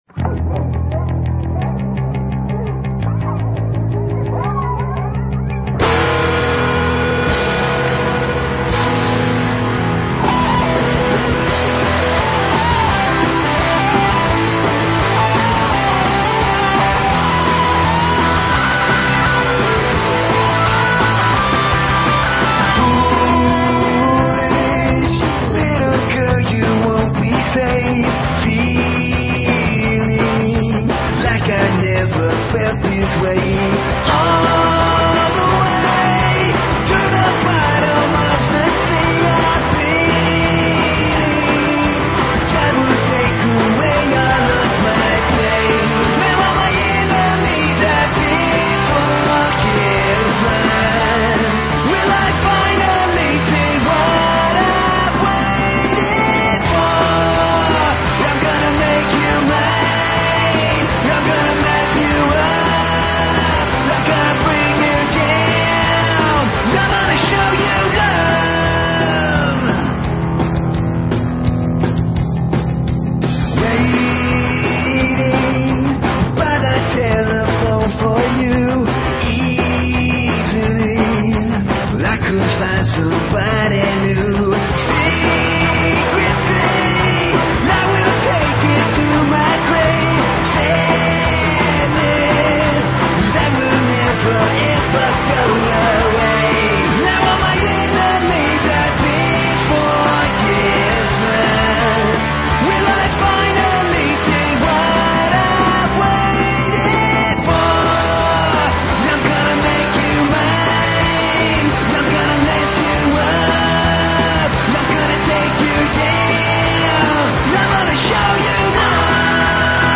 metal
punk
rock
high energy rock and roll